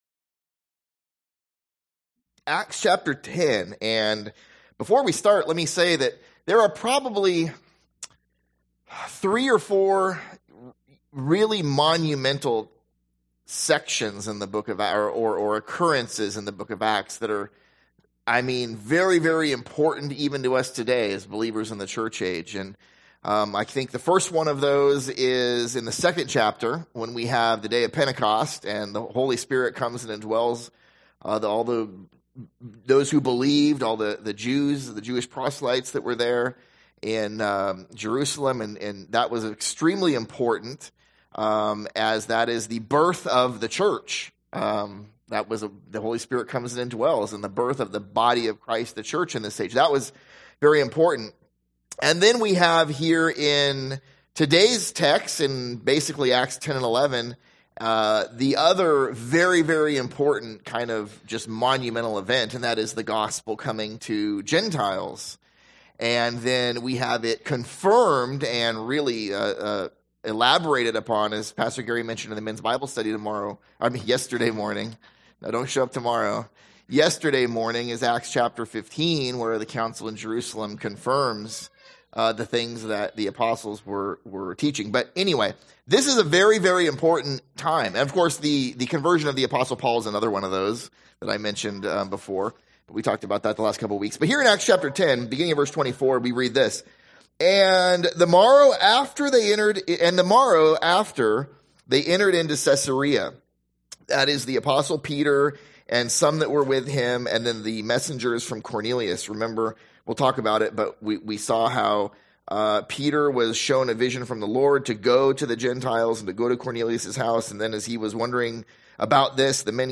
/ A Sunday School series through the book of Acts